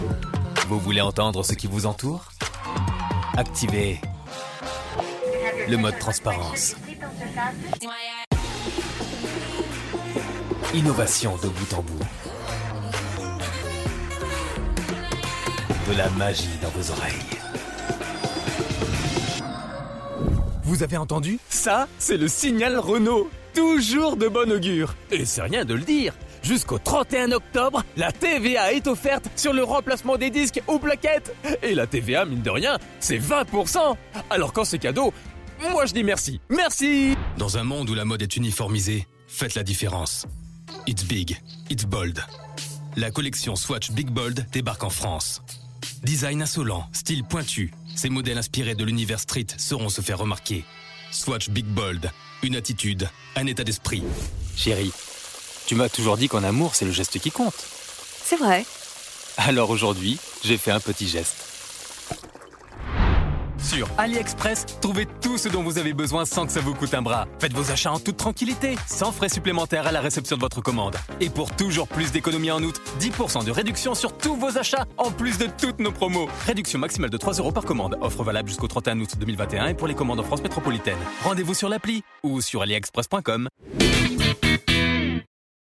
Amicale, Polyvalente, Jeune, Naturelle, Mature
Commercial